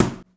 explosion.wav